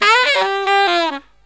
63SAXMD 09-L.wav